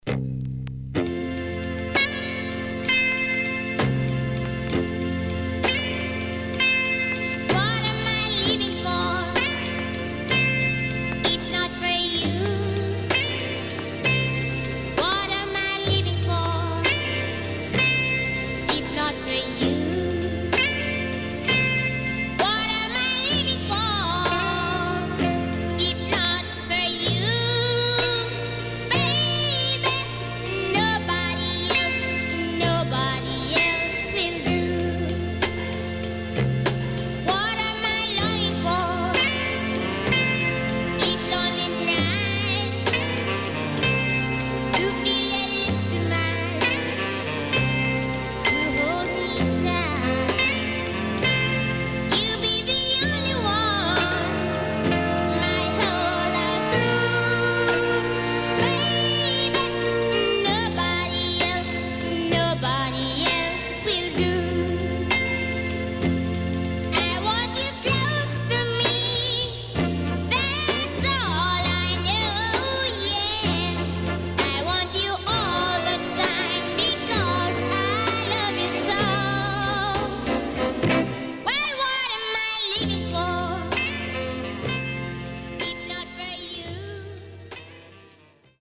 HOME > SKA / ROCKSTEADY
SIDE A:少しチリノイズ入りますが良好です。